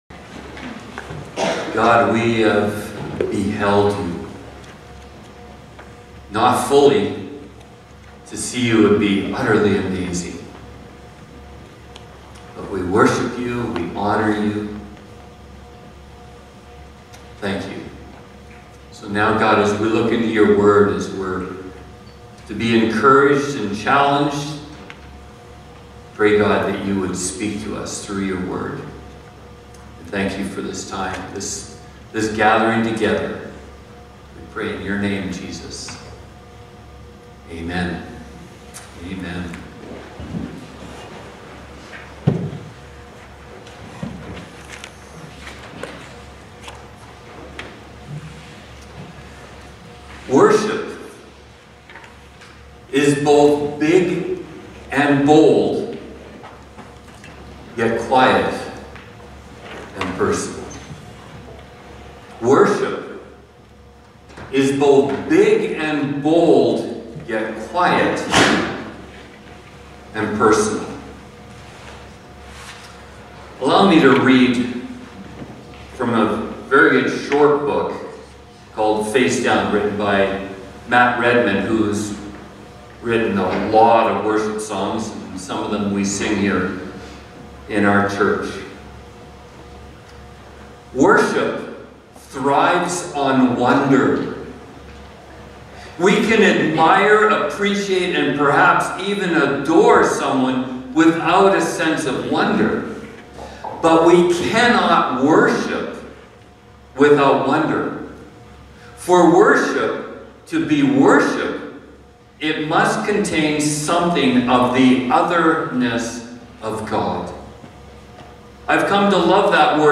Sermons | Rosenort Community Church